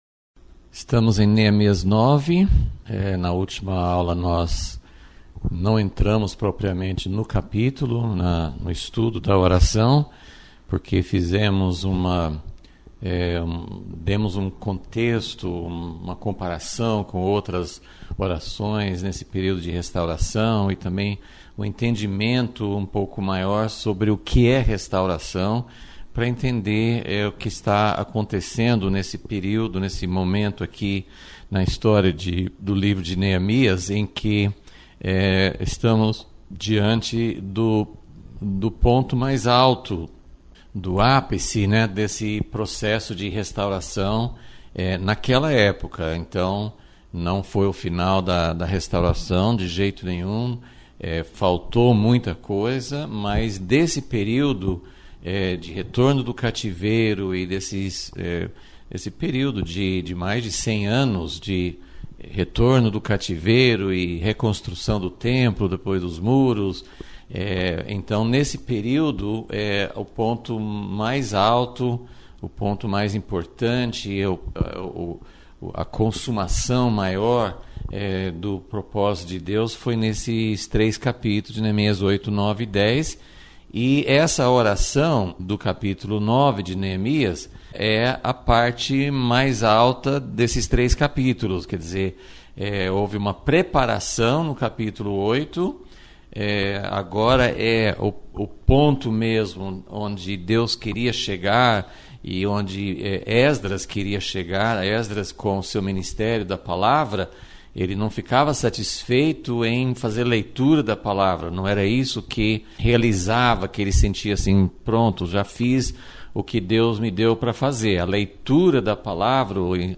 Aula 24 – Vol.36 – A oração mais coletiva da Bíblia